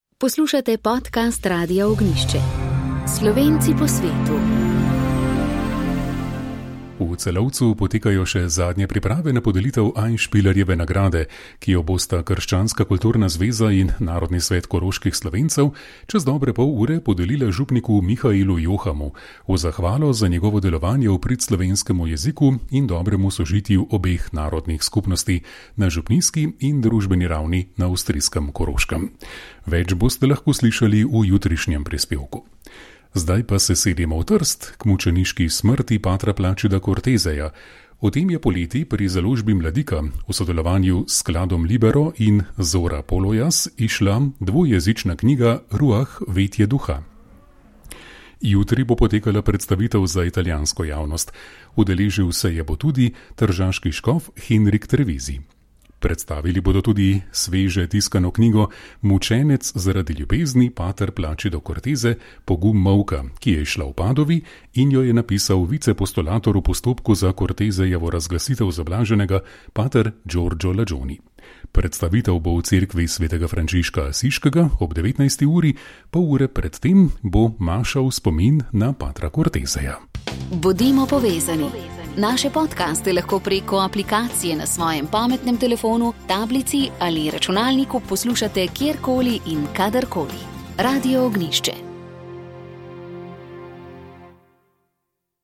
Slovenska kulturna akcija SKA iz Argentine letos obeležuje 70-letnico delovanja. Tako je 26. junija 2024 v dvorani Slovenske matice v Ljubljani pripravila slavnostno akademijo in posvet.